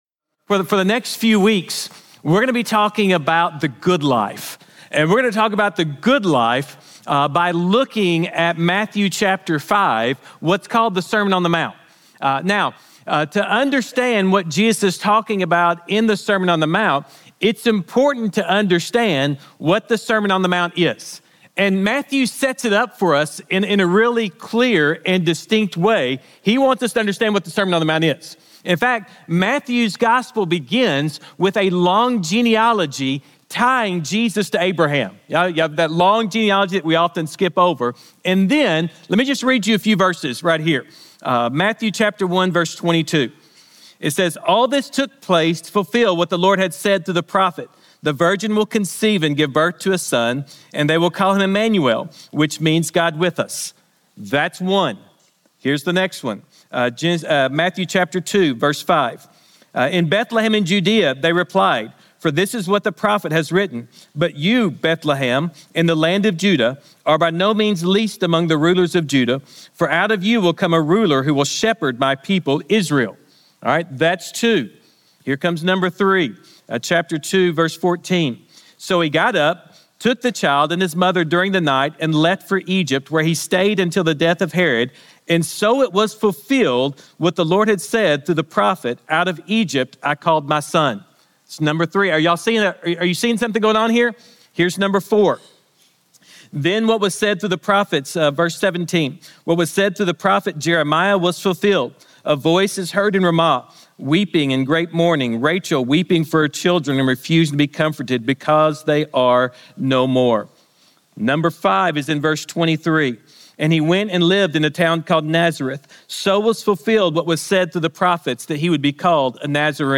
Sermons | Grace Community Church